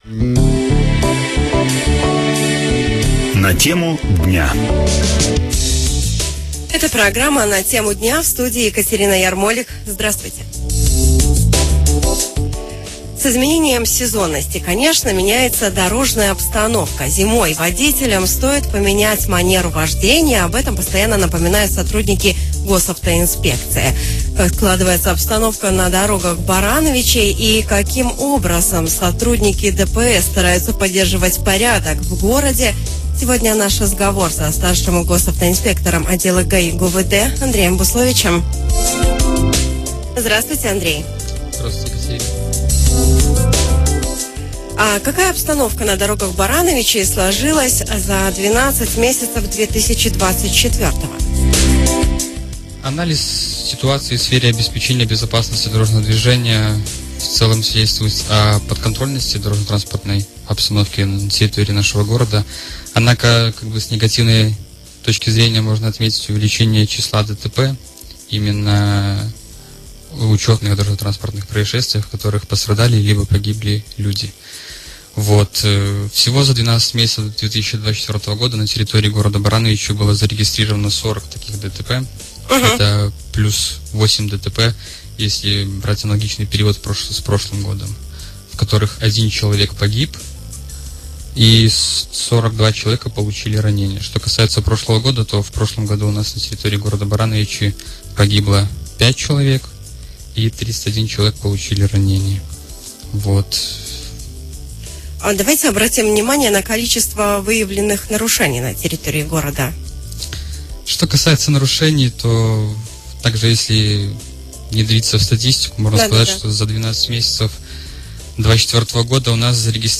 наш разговор